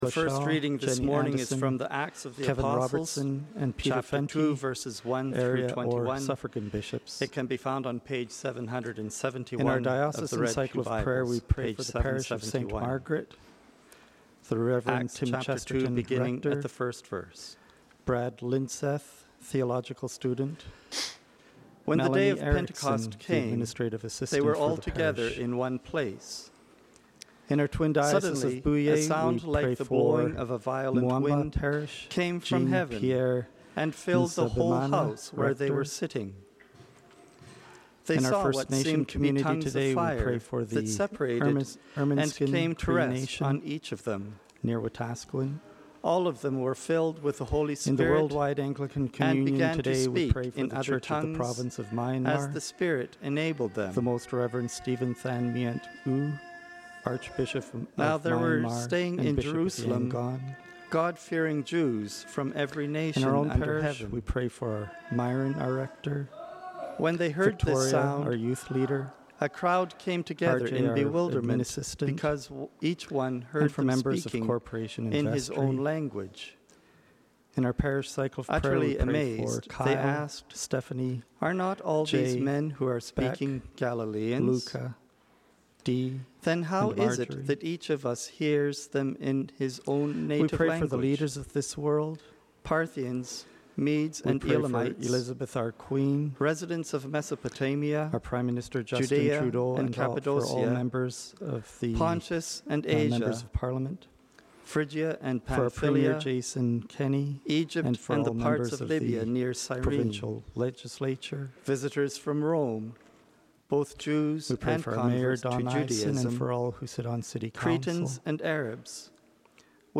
Download Download Sermon Notes 2019-06-09 Jun 09 Sunday Bulletin.docx Children Focused Service The scripture readings and prayers of the people in the recording somehow got overlaid. The Gospel reading starts at 8:22 on the recording and the technical issue seems resolved at that point.